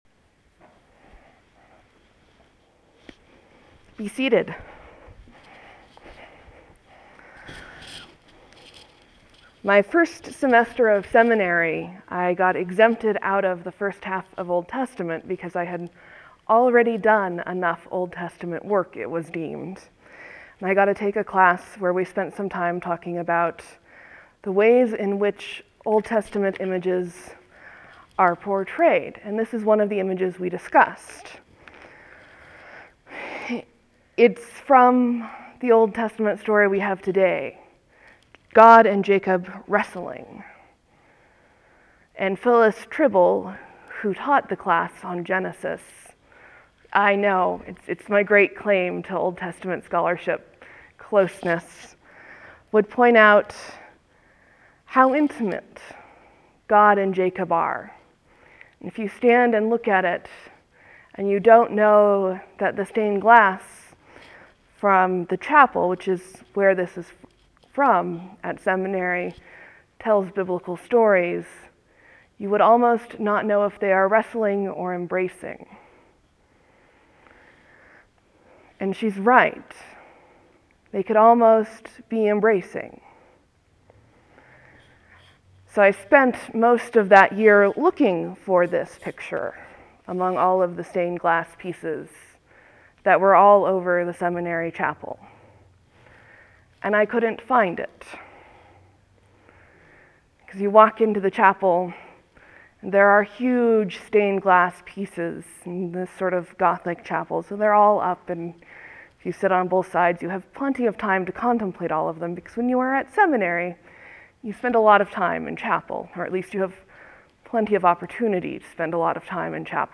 (There will be a few moments of silence before the sermon starts.